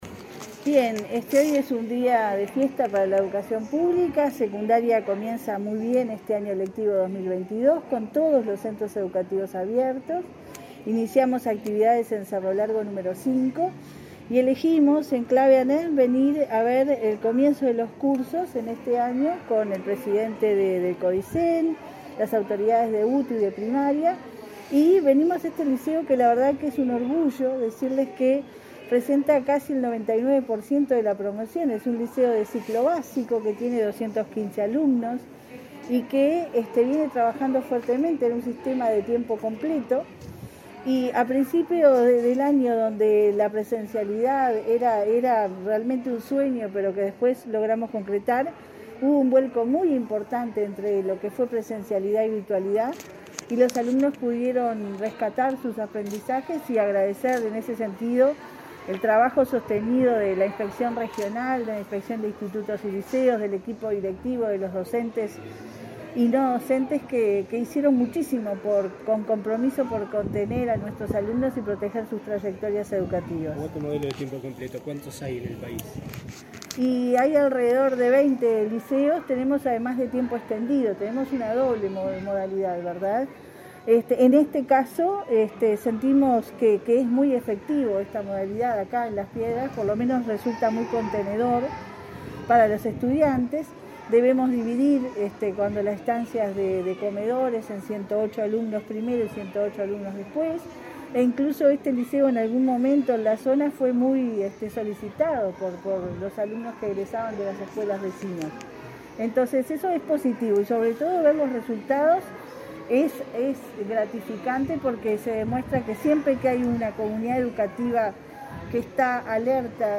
Declaraciones a la prensa de la directora de Educación Secundaria, Jenifer Cherro
La directora de Secundaria, Jenifer Cherro, dialogó con la prensa, luego de realizar una recorrida por centros educativos de Canelones, junto con el